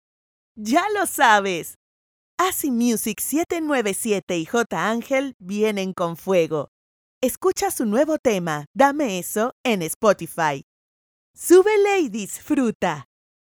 Female
Character, Confident, Conversational, Corporate, Friendly, Natural, Young
Demo_e_learning_2024.mp3
Microphone: Audio-Technica AT4030a Cardioid Condenser Microphone